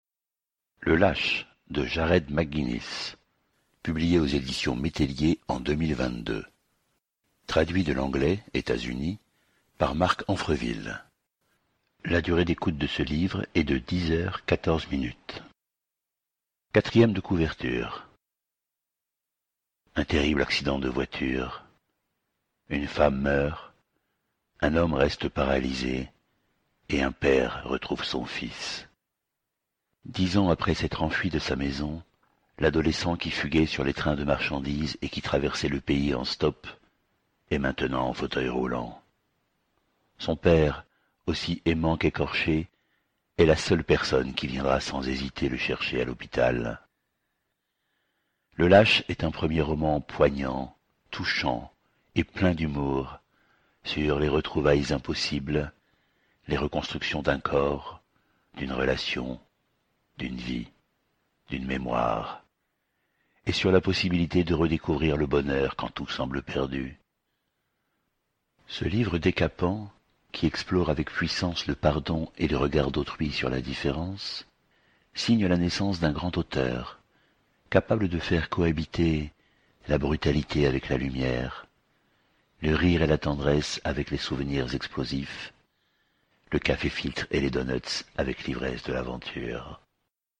QUELQUES EXEMPLES DE LECTURE DES AUDIOLIVRES :